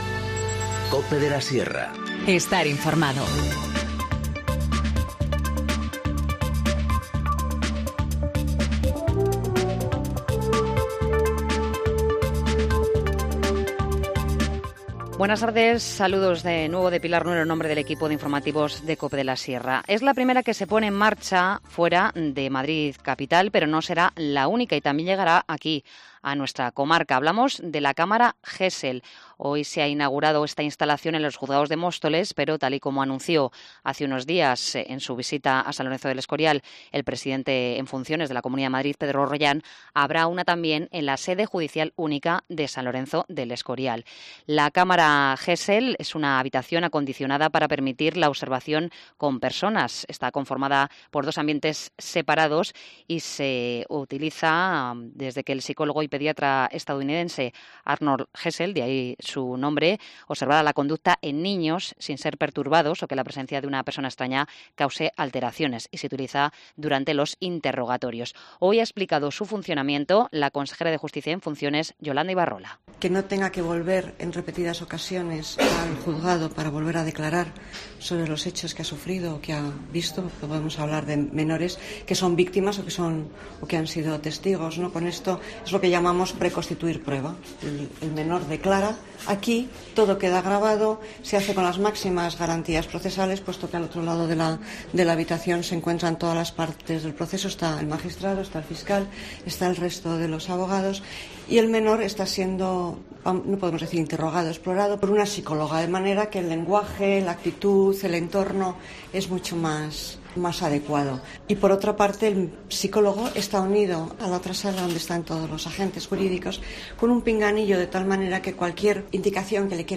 Informativo Mediodía 11 julio 14:50h